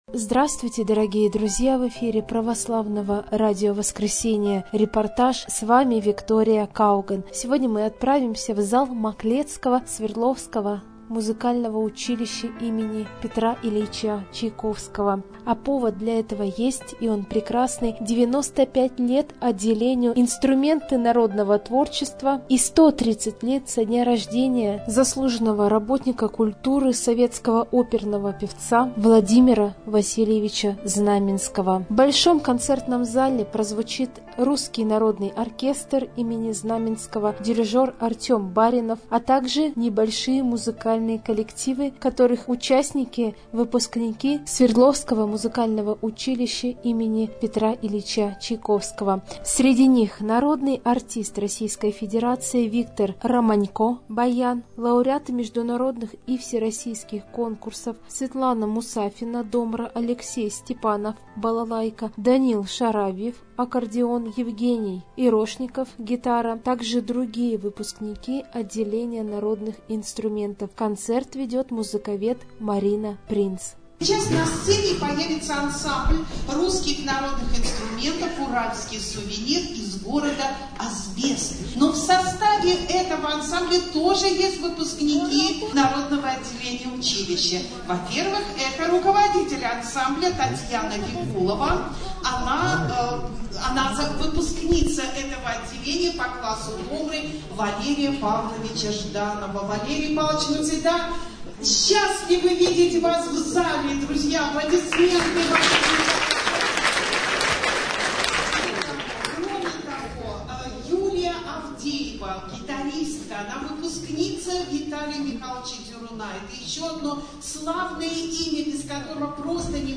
Юбилейный концерт в училище Чайковского
yubilejnyj_koncert_v_uchilishche_chajkovskogo.mp3